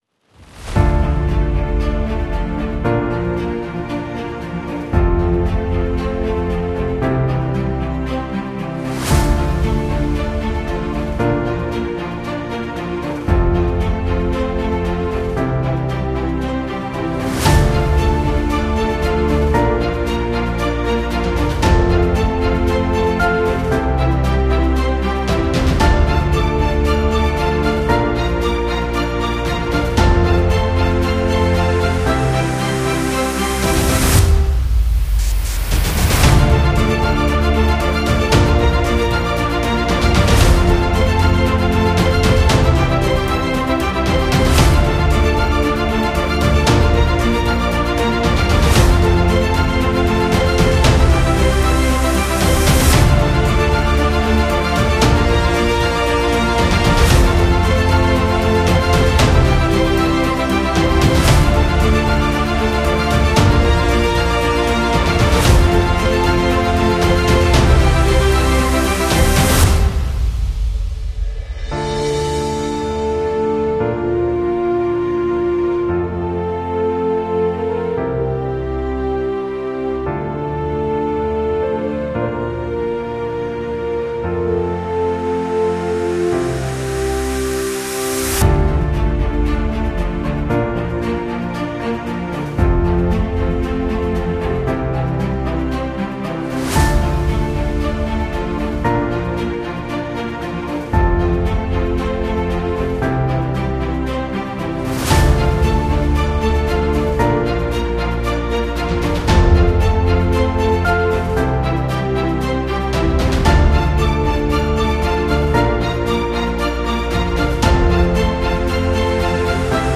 宣传片震撼大气背景音乐